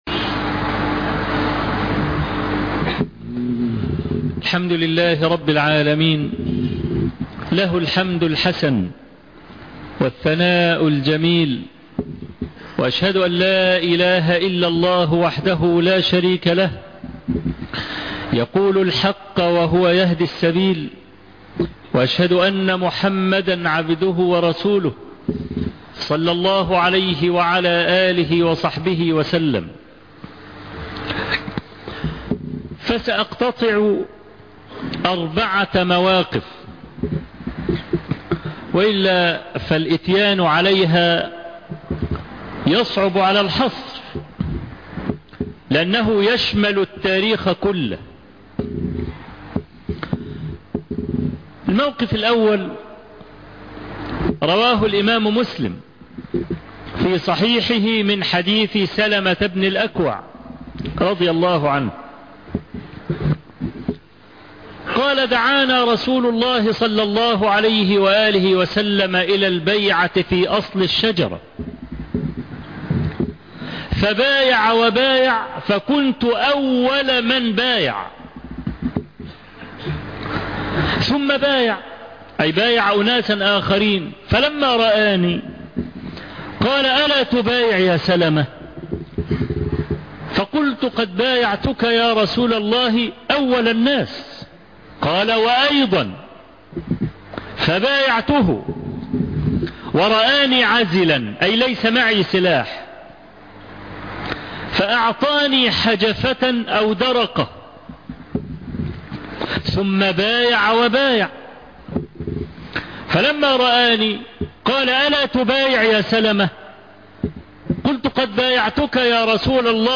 خطبة قيمة أربعـــــة مواقف مع النبي ﷺ للتاريخ - الشيخ أبو إسحاق الحويني